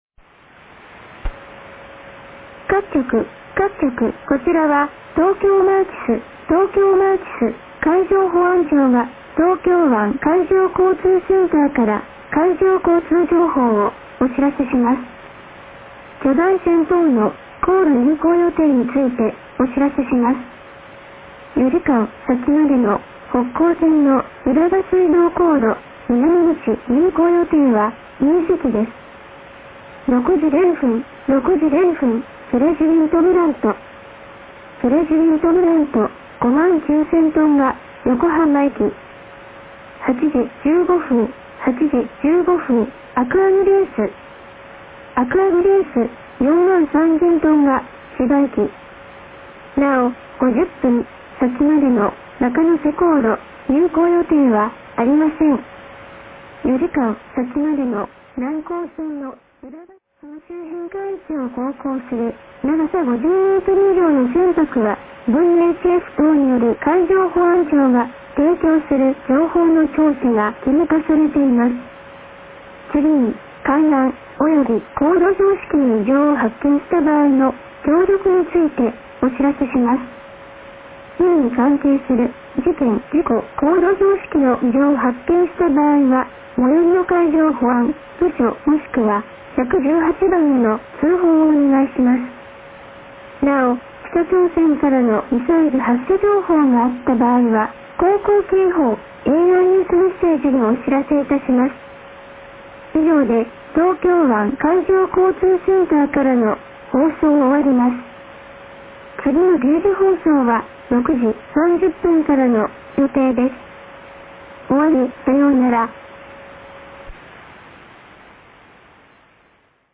最後に9日朝06時台前半に東京湾荒川河口で受信した東京マーチスの受信音、開始1分と終了1分部分をUPします。
<受信地：東京都江東区新砂 東京湾荒川河口 RX:ICF-SW7600GR ANT:AN-12>